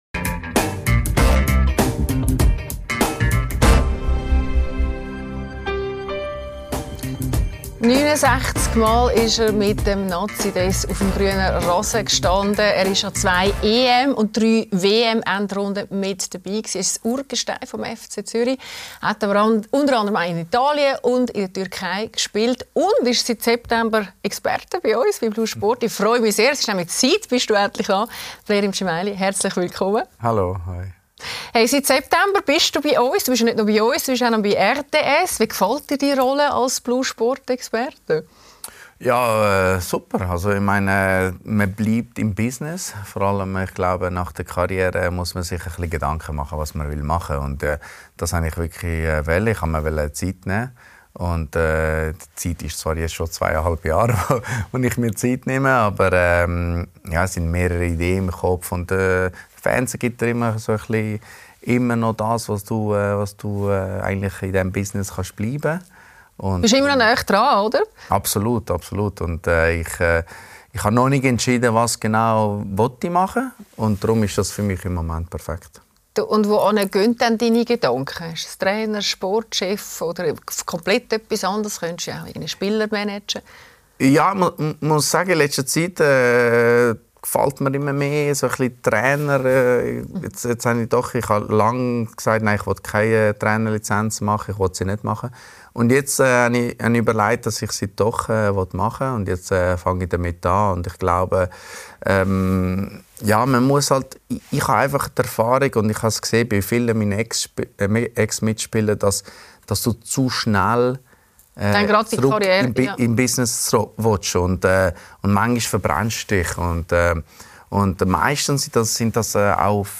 Mit Blerim Dzemaili ~ LÄSSER ⎥ Die Talkshow Podcast